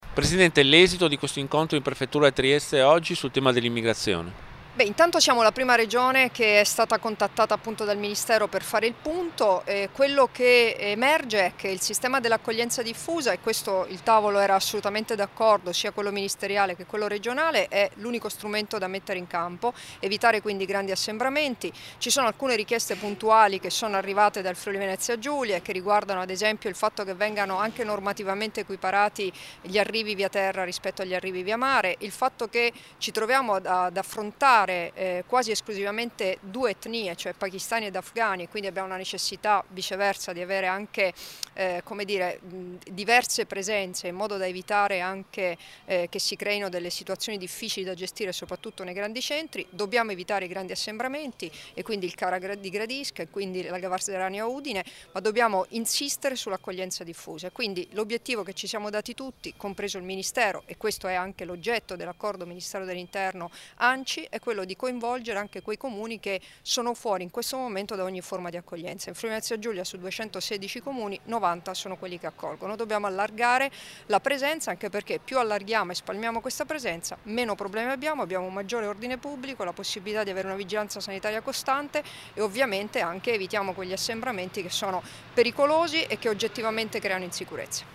Dichiarazioni di Debora Serracchiani (Formato MP3) [1417KB]
a margine dell'incontro in Prefettura sull'accoglienza di migranti, rilasciate a Trieste il 9 gennaio 2016